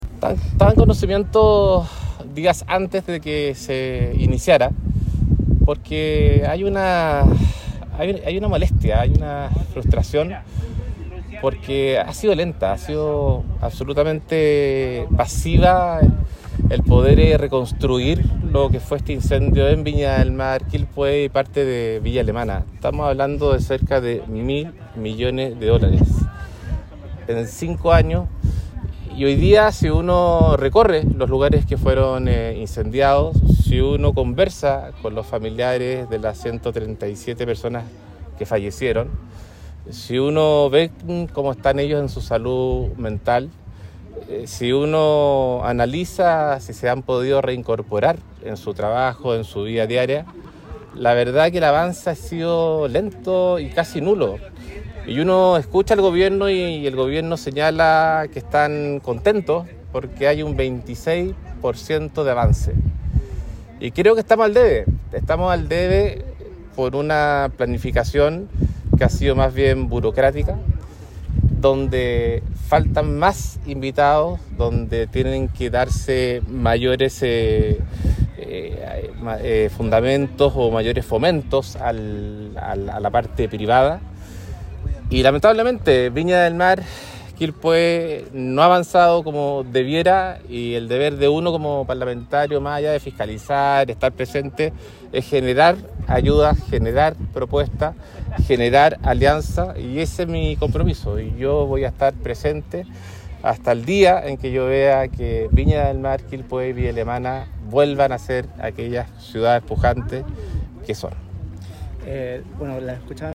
En ese sentido, el diputado Andrés Celis se hizo presente y criticó la ineficiencia del Gobierno en la gestión de la ayuda a los afectados y aseguró que estaba en conocimiento de la huelga de hambre antes de que comenzara.